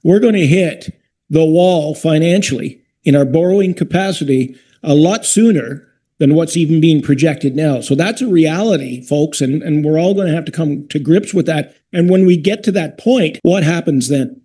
Councillor Jim Boldt provided this warning about relying too heavily that form of financing.